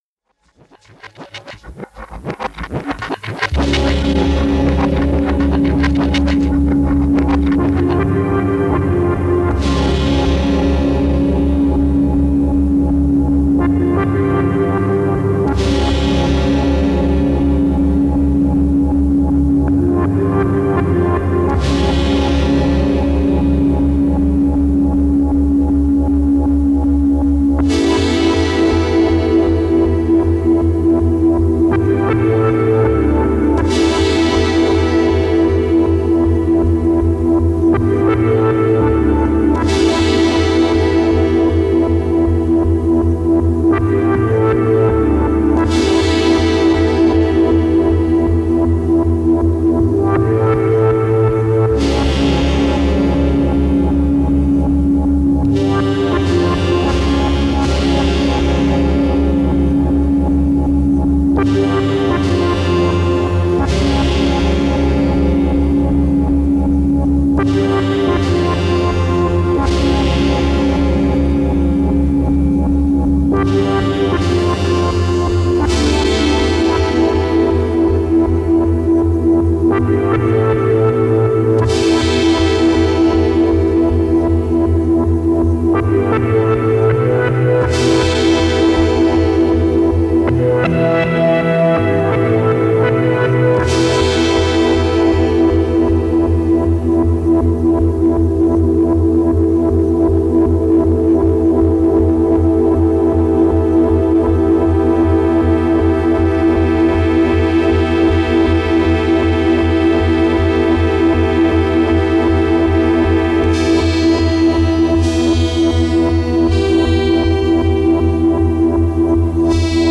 Genre: Electronic.